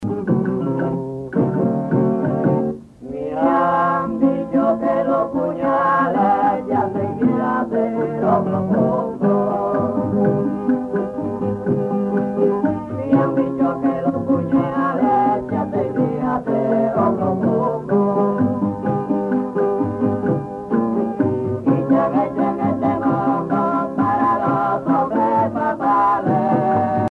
Parranda
Sancti Spiritus, Cuba
Pièce musicale inédite